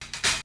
Medicated OHat 5.wav